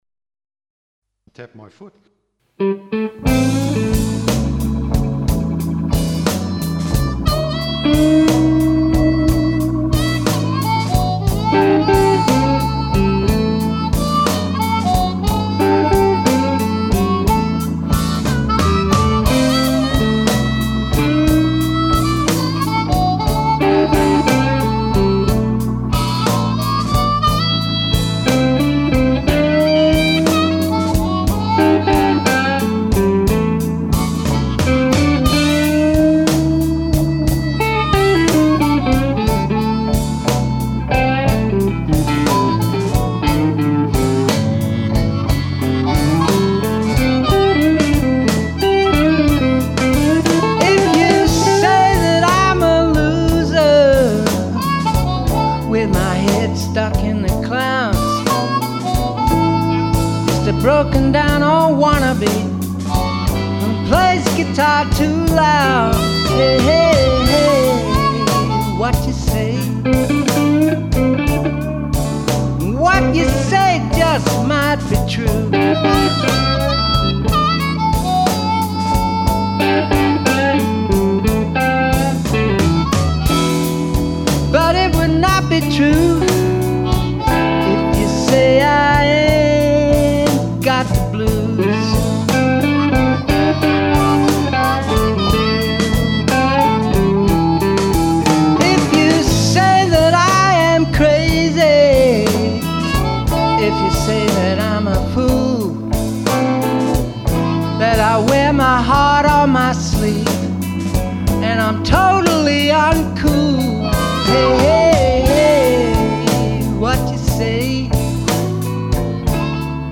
harmonica